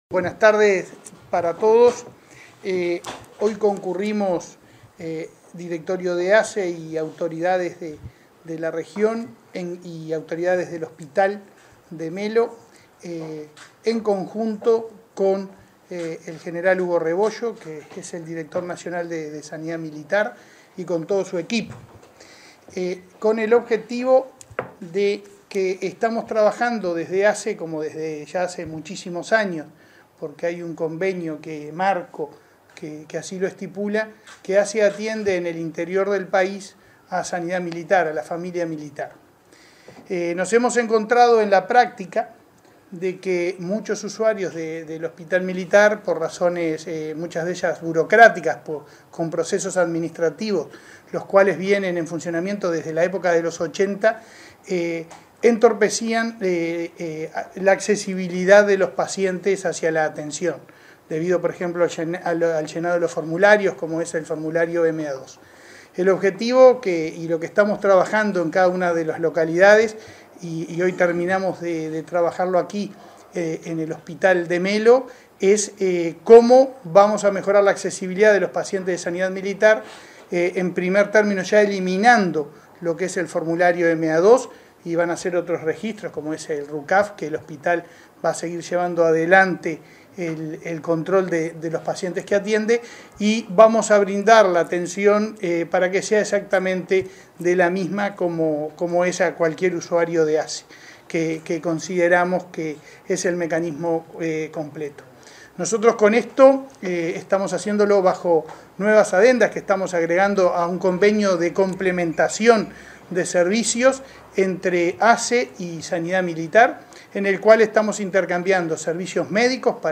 Palabras del presidente de ASSE, Leonardo Cipriani
En el marco de su visita al hospital de Melo, este 17 de mayo, se expresó el presidente de la Administración de los Servicios de Salud del Estado